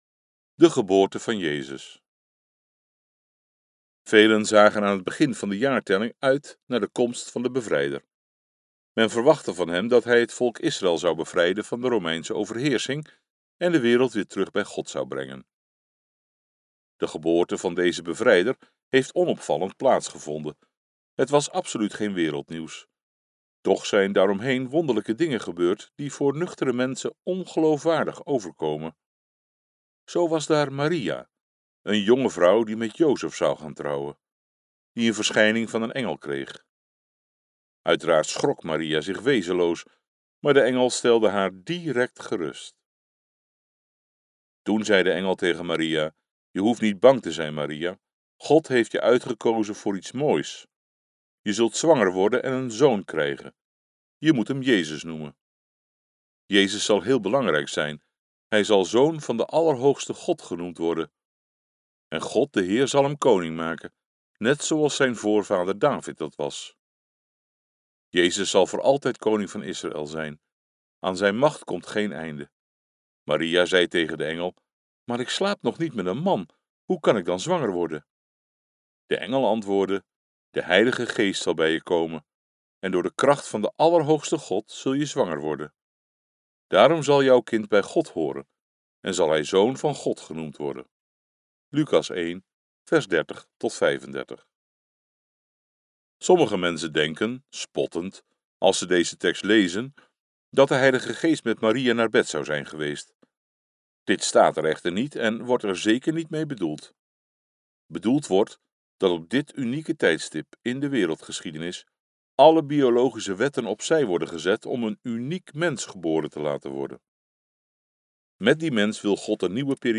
Christelijke Luisterboeken - Scholten Uitgeverij - Christelijke boeken